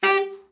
TENOR SN  24.wav